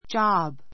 job 小 A1 dʒɑ́b ヂャ ブ ｜ dʒɔ́b ヂョ ブ 名詞 ❶ 職 , 勤め口, 仕事 get a job get a job 職を得る, 就職する lose a job lose a job 職を失う, 失業する He is out of a job and goes out to look for one (=a job) every day.